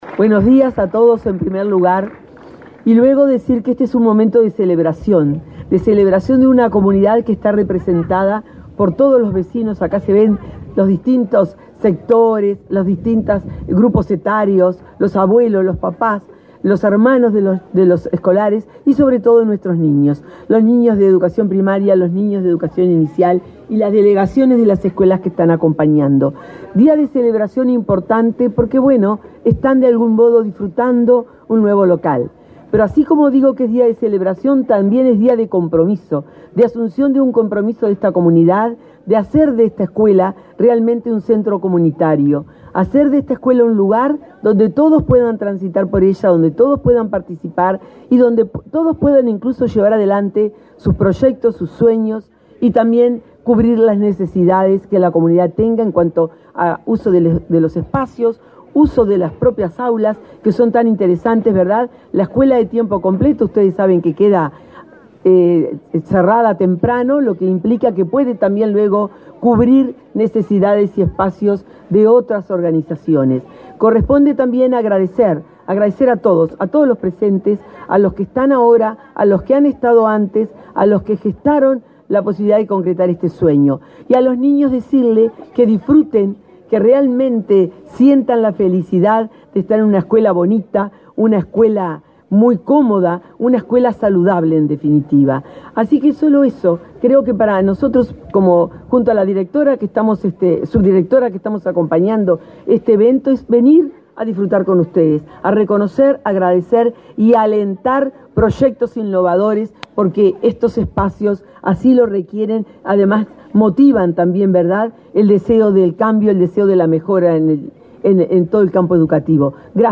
Conferencia de prensa por la inauguración del edificio de la escuela n.° 96 de Punta del Diablo
Este 29 de julio quedó inaugurado la sede de la escuela n.° 96, de tiempo completo, en Punta del Diablo, departamento de Rocha. Participaron en el evento el presidente del Consejo Directivo Central de la Administración Nacional de Educación Pública, Robert Silva, y la directora general de Primaria, Graciela Fabeyro.